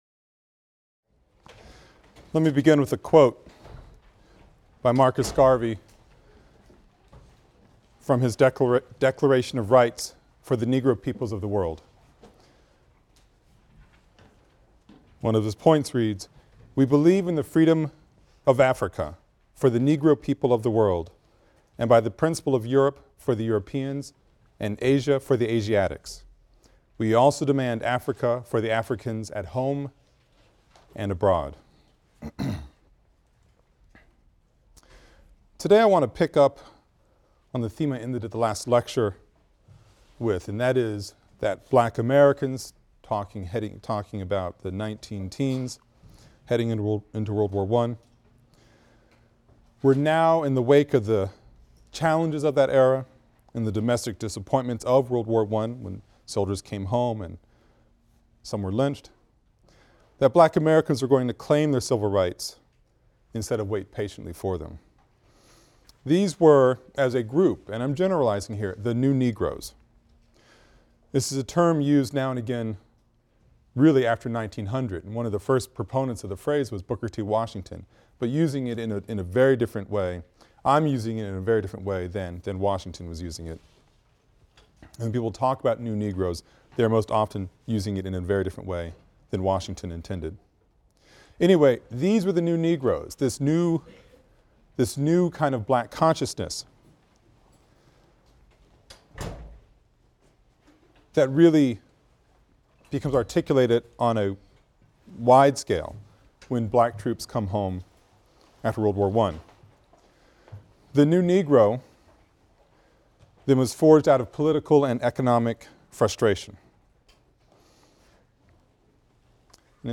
AFAM 162 - Lecture 9 - The New Negroes | Open Yale Courses